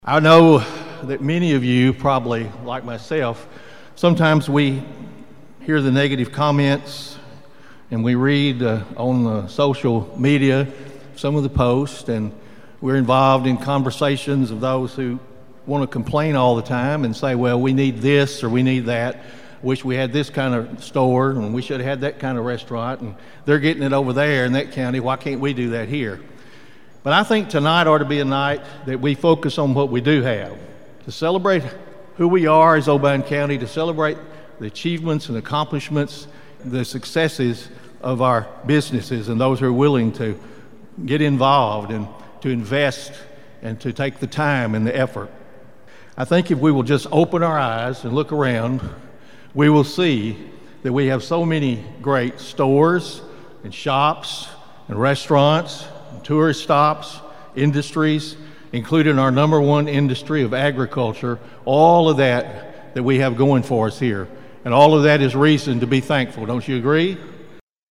During last week’s annual Chamber of Commerce banquet, Mayor Carr urged residents to take a positive approach to what Obion County has to offer.(AUDIO)